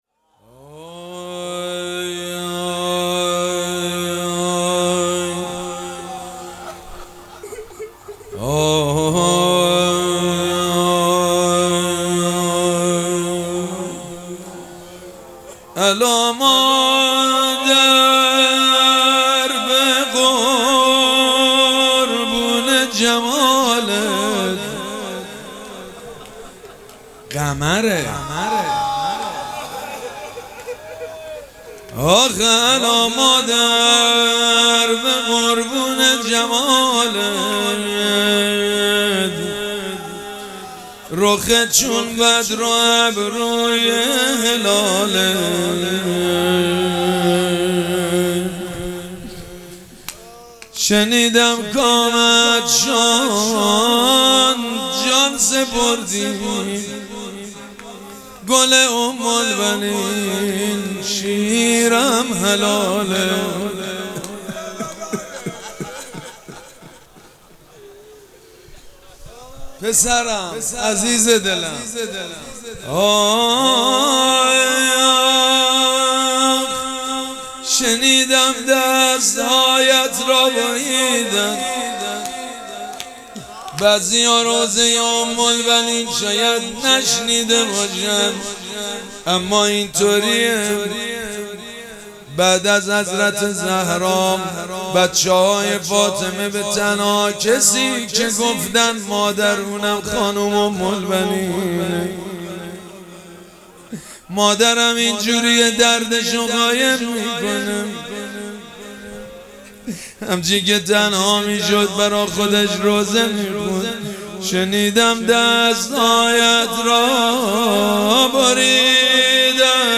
شب چهارم مراسم عزاداری اربعین حسینی ۱۴۴۷
روضه
حاج سید مجید بنی فاطمه